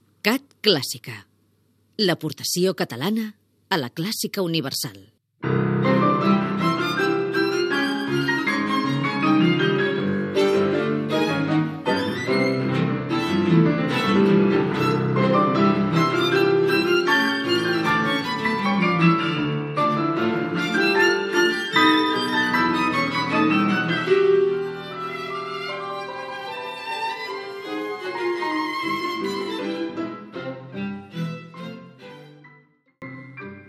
Indicatiu del canal i tema musical